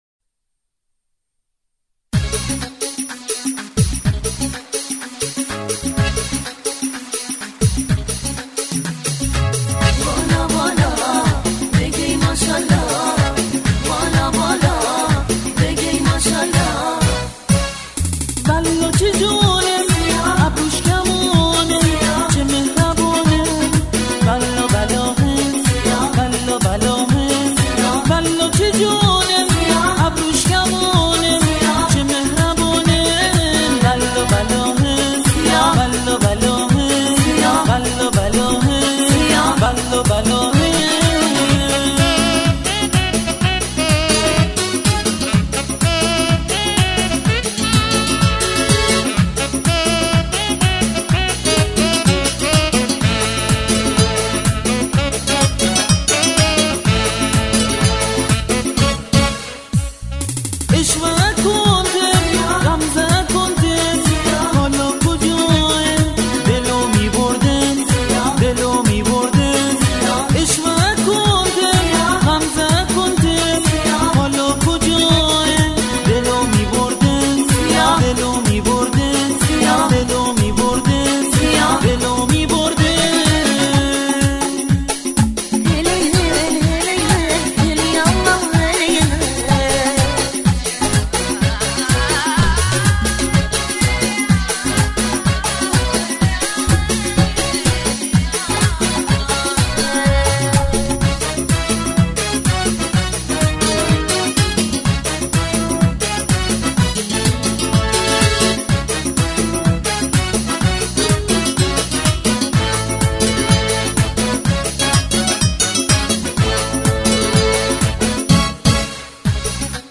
تمام تراک‌های این آلبوم به سبک و گویش بندری است.